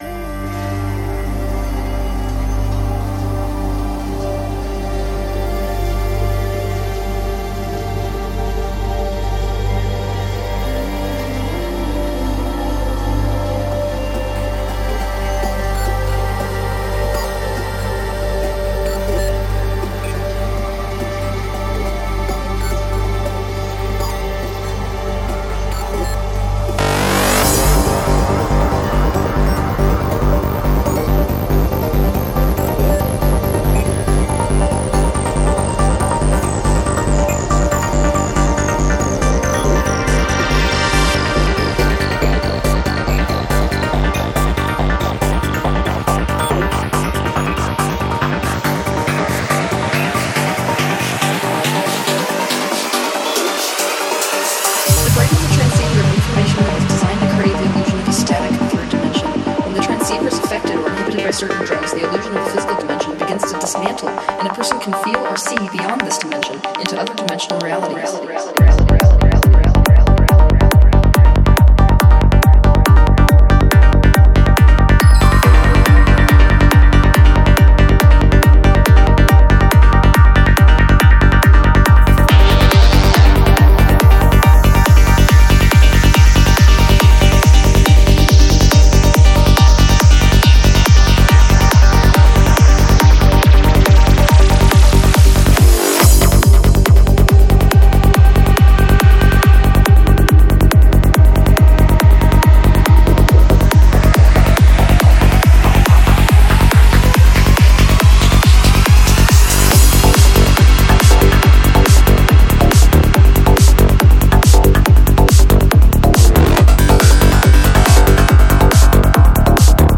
Жанр: Electro
Альбом: Psy-Trance